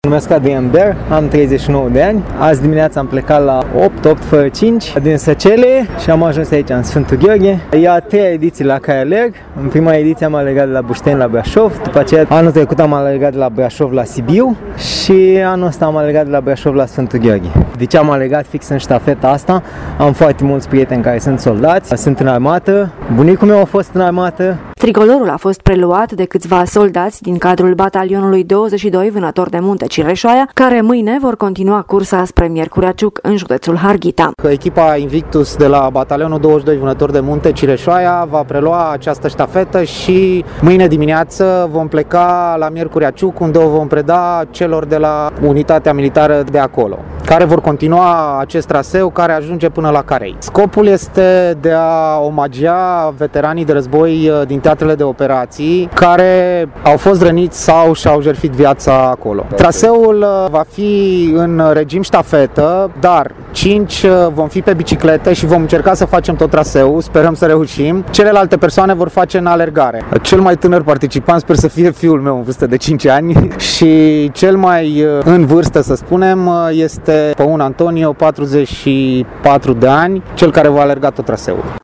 Festivitatea de predare a ştafetei a avut loc la orele amiezii în faţa statuii Ostaşului Român din Sfântu Gheorghe.